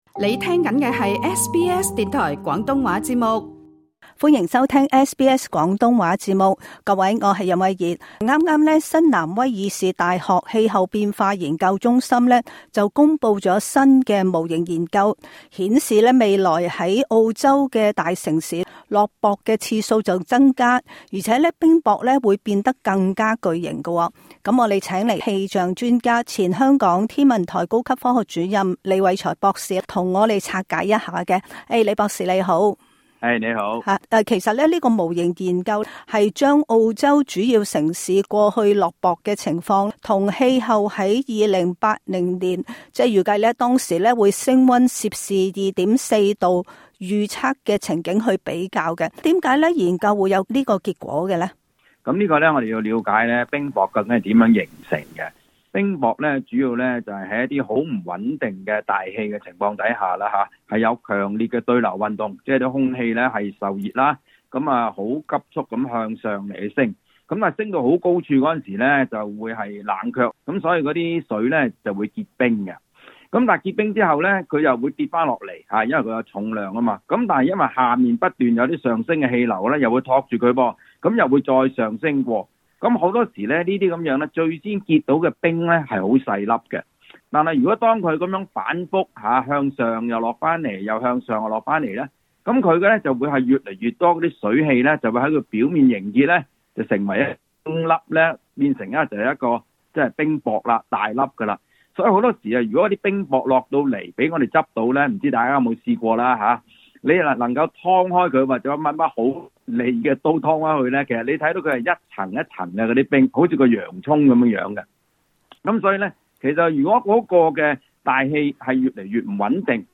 詳情請聽今集訪問。